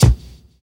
Kick (3).wav